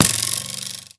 arrow.wav